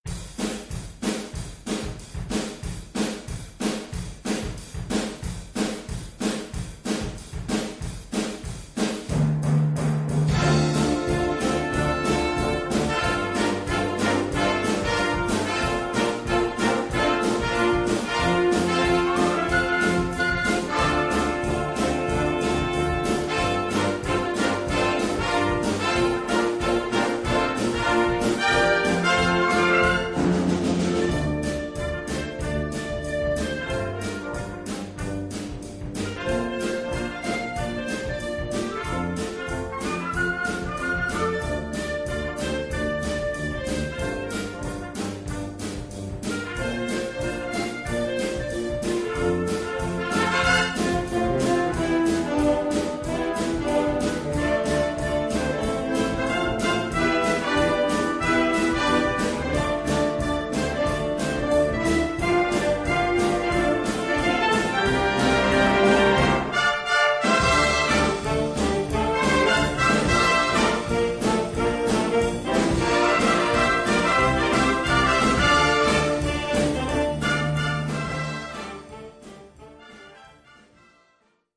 Gattung: Poptitel
Besetzung: Blasorchester